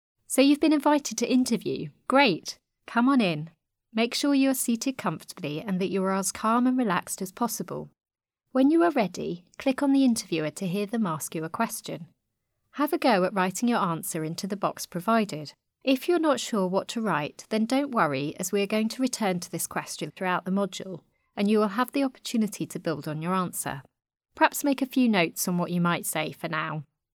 Hear Question Interview question audio (MP3) What was the most challenging thing you had to deal with on your clinical placements (or work experience) so far?